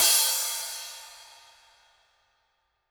soft-hitfinish.mp3